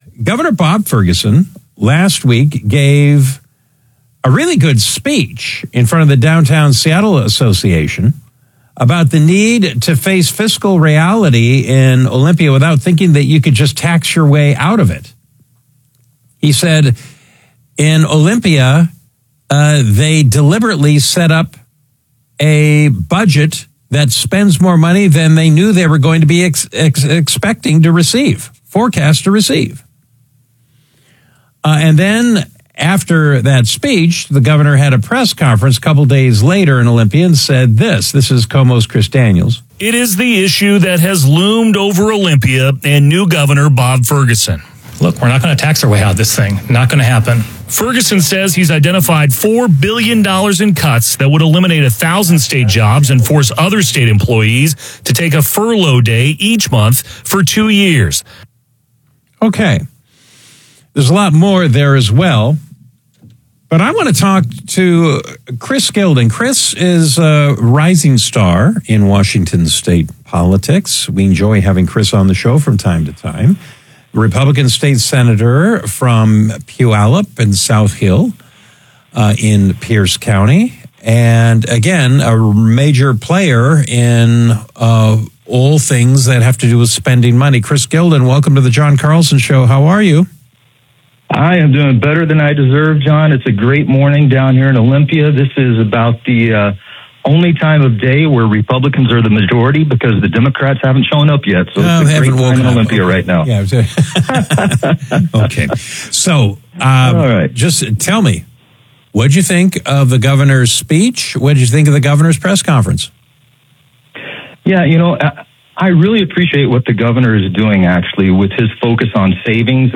KVI Radio